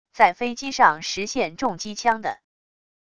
在飞机上实现重机枪的wav音频